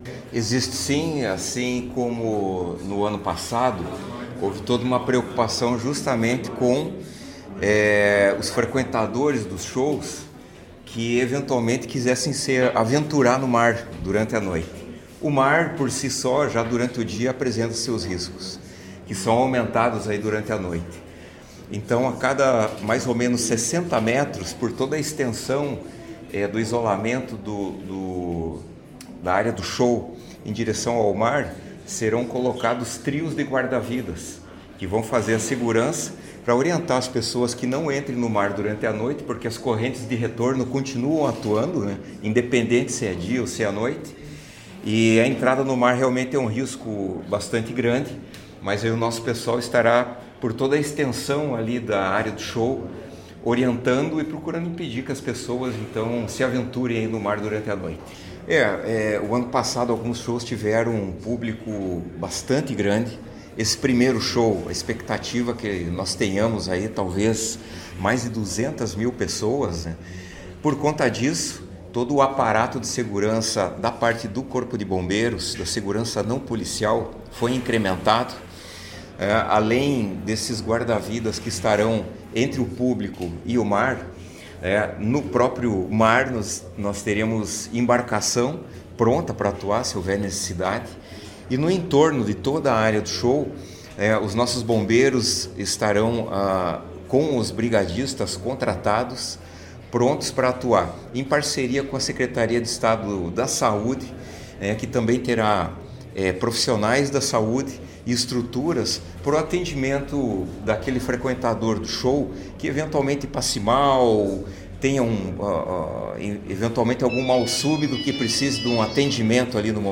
Sonora do comandante-geral do Corpo de Bombeiros Militar do Paraná, coronel Antonio Hiller Lino, sobre o reforço no policiamento para os shows do Verão Maior